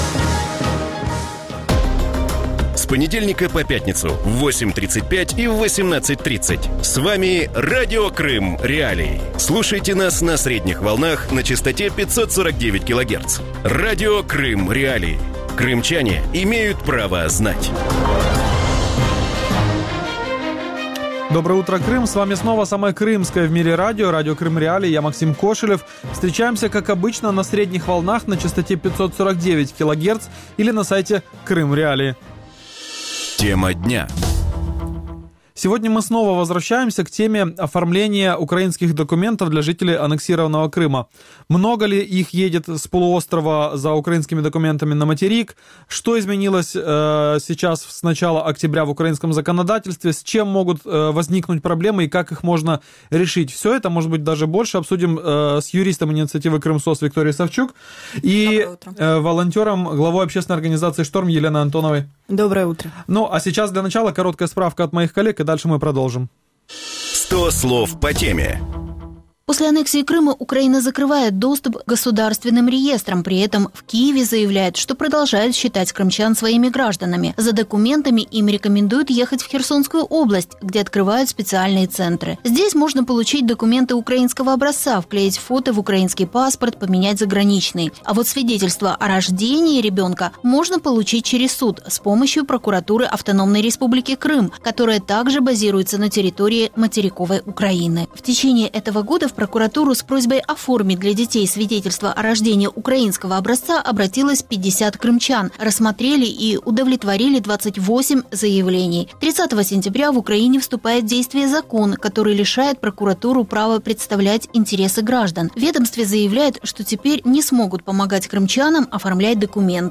Центри з надання адміністративних послуг для кримчан на адмінкордоні з анексованим Росією Кримом не полегшать жителям півострова процедуру отримання чи заміни документів українського зразка. Таку думку в ранковому ефірі Радіо Крим.Реалії